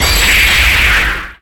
The Finish Zoom sound effect in Super Smash Bros. Ultimate.